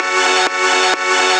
VTDS2 Song Kit 22 Male Out Of My Mind FX Synth Fill.wav